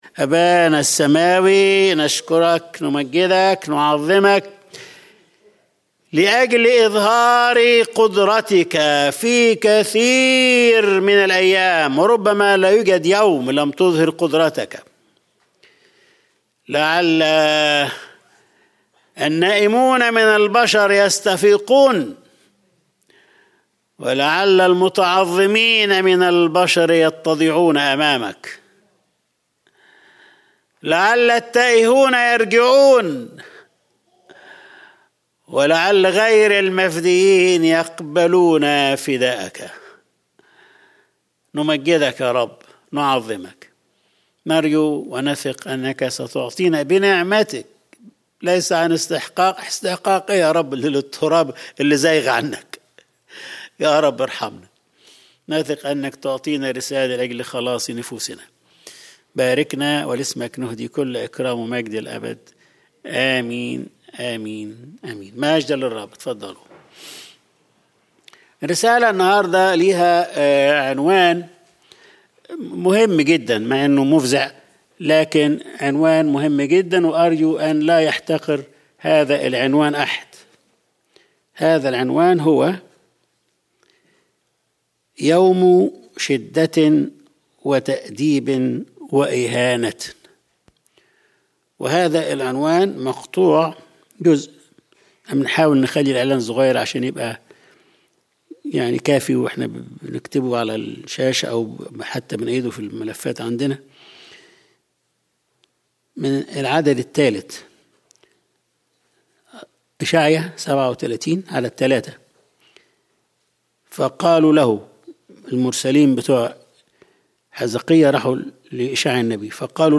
Sunday Service | يوم شدَّة وتأديب وإهانة